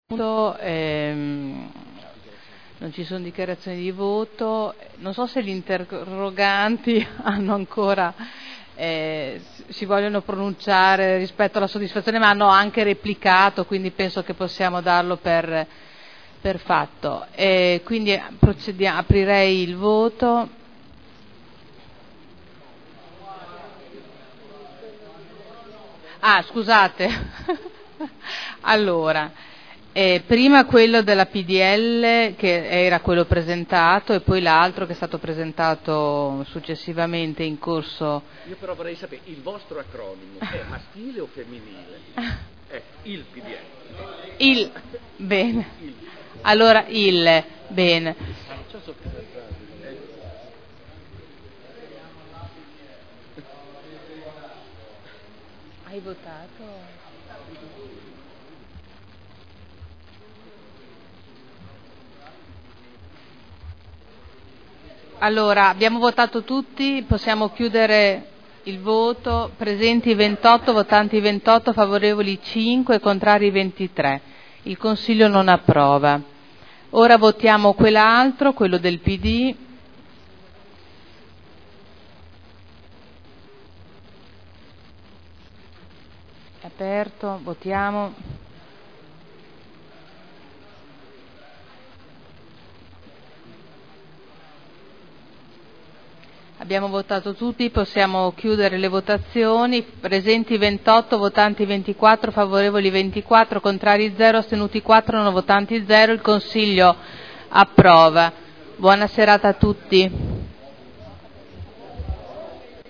Presidente — Sito Audio Consiglio Comunale
Seduta del 01/02/2010.